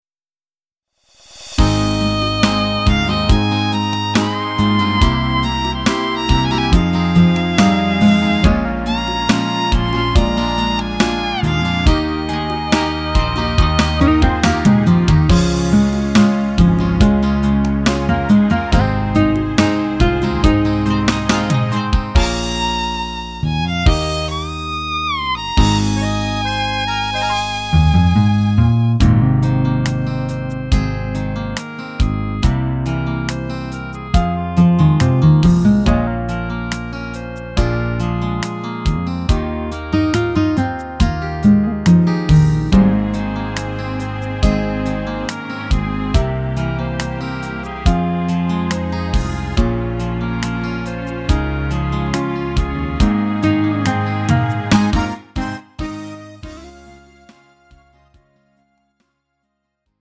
음정 -1키 3:40
장르 가요 구분 Lite MR
Lite MR은 저렴한 가격에 간단한 연습이나 취미용으로 활용할 수 있는 가벼운 반주입니다.